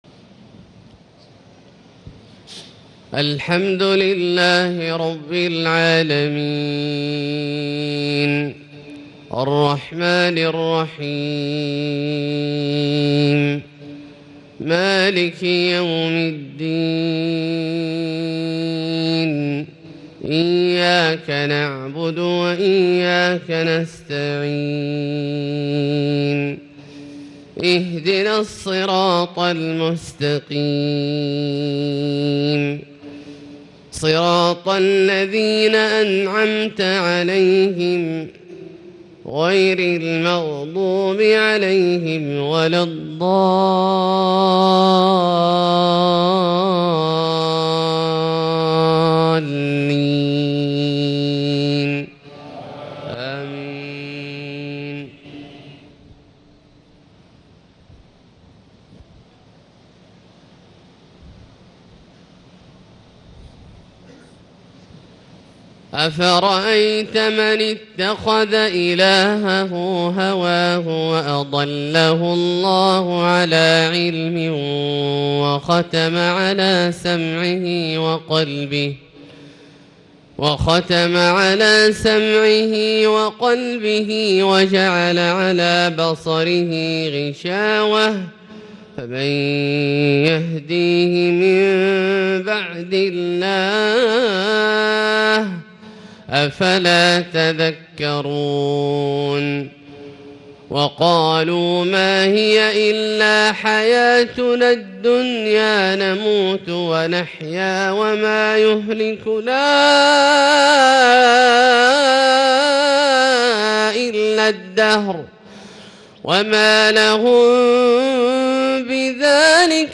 الشيخ عبدالله الجهني يتألق ويترنم بتلاوة محبرة من سورة الجاثية{23-37} عشاء 8-7-1439هـ > ١٤٣٩ هـ > الفروض - تلاوات عبدالله الجهني